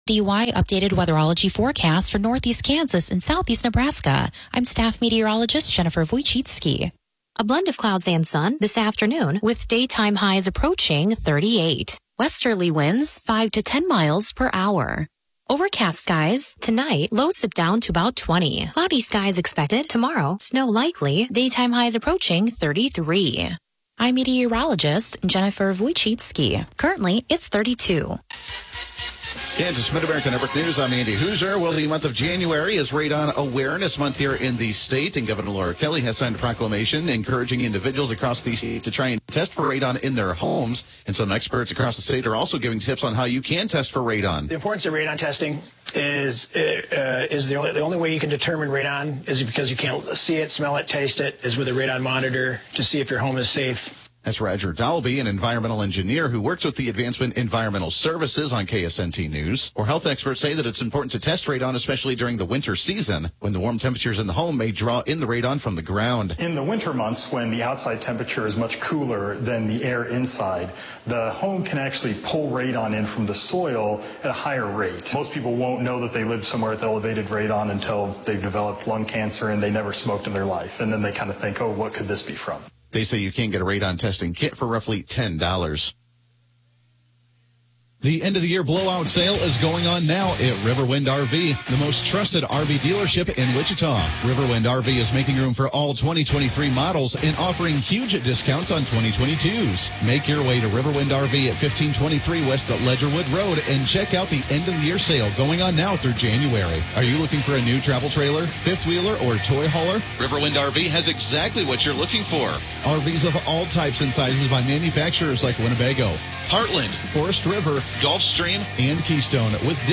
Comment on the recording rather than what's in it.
The KNDY News: Midday Edition podcast gives you local, regional and state news as well as relevant information for your farm or home as well. Broadcasts are archived daily as originally broadcast on Classic Country AM 1570/FM 94.1 KNDY.